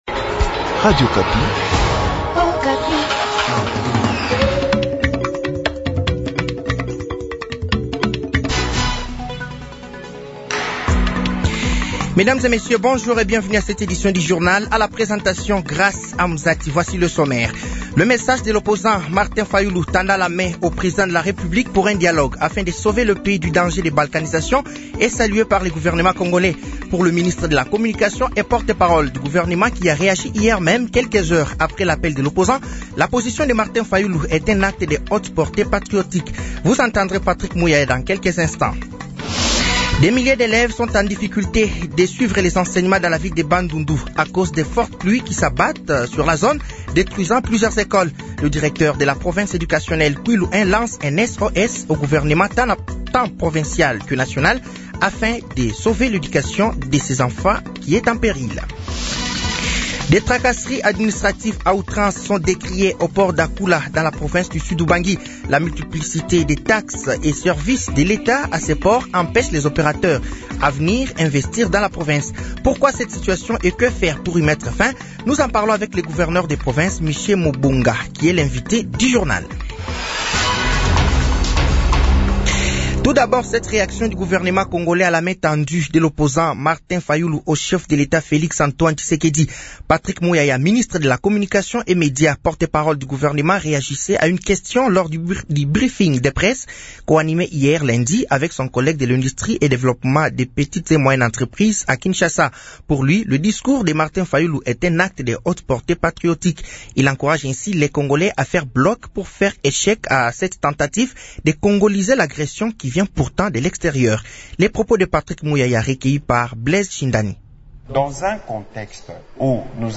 Journal français de 12h de ce mardi 03 juin 2025